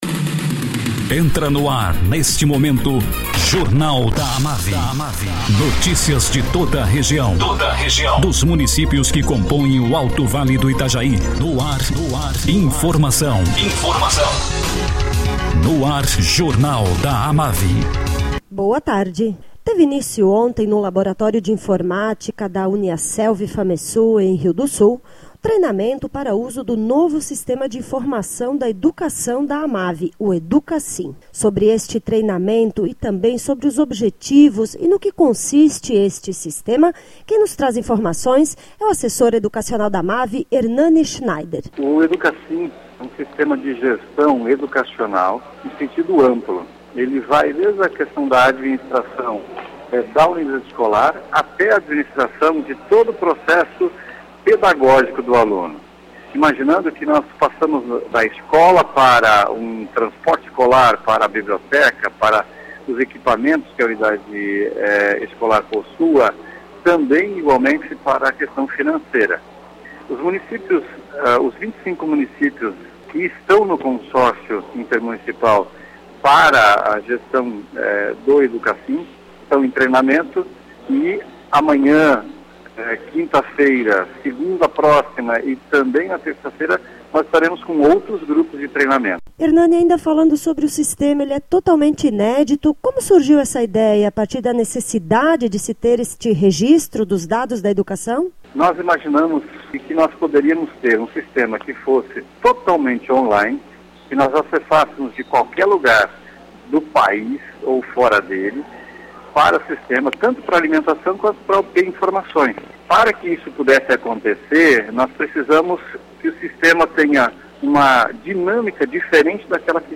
Acervo de boletins